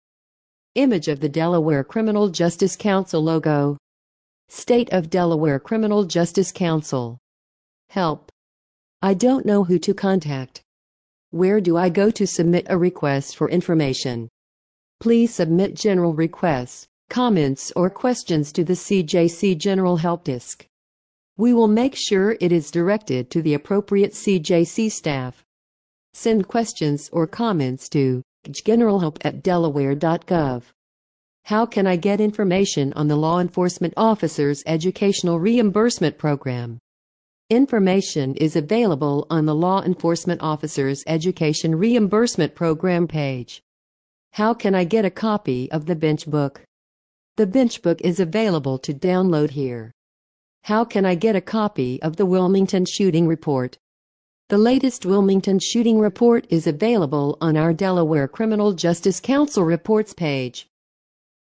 Listen to this page using ReadSpeaker